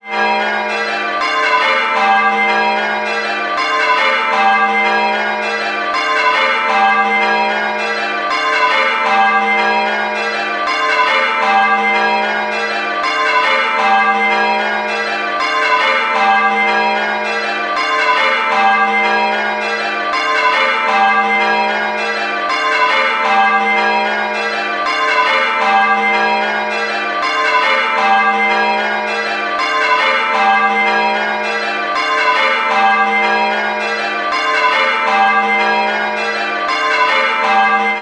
Pembroke Bells Ringtone Android.mp3